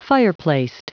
Prononciation du mot fireplaced en anglais (fichier audio)
Prononciation du mot : fireplaced